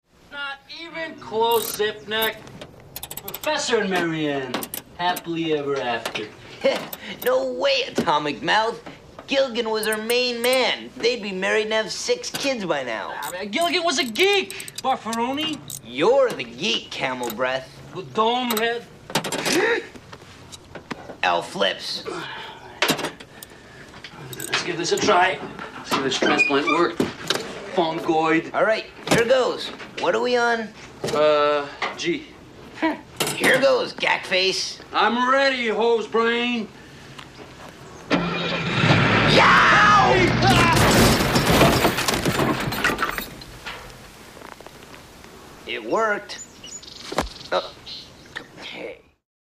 The Alphabetical Insult Game. In the first TMNT movie, while Donnie (who, in case you forgot, is voiced by 80s icon Corey Feldman) is working on the truck with Casey Jones, he comes up with what has to be one of the greatest games ever invented, but you don't figure out what they're up to until more than halfway into the scene (
They call each other stupid, second-grade, G-rated names... in alphabetical order!